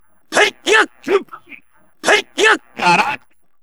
Funk Sample Free No Beat Sound Button - Free Download & Play